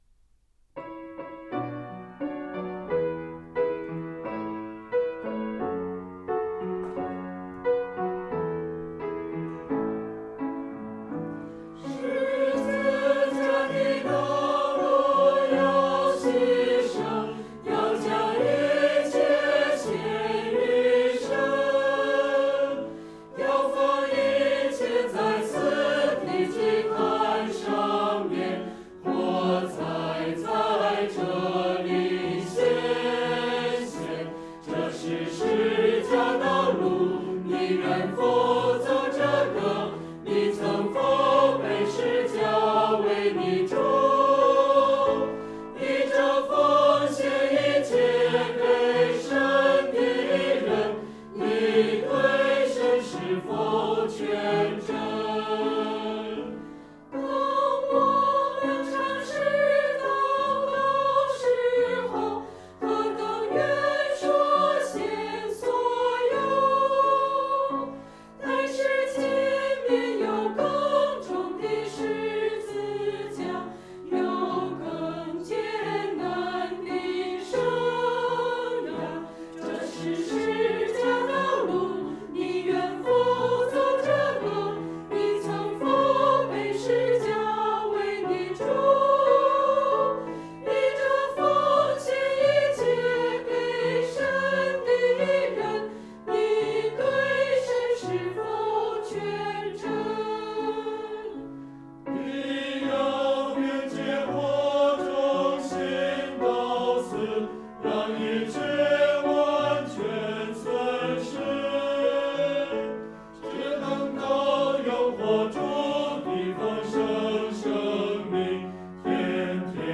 回应诗歌：十字架的道路要牺牲（58，新515）